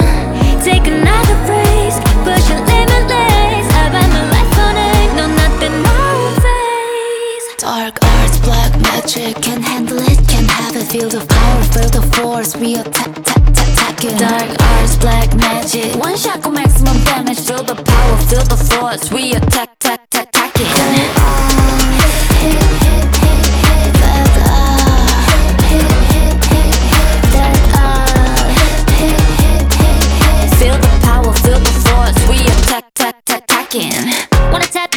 Скачать припев
2025-07-15 Жанр: Поп музыка Длительность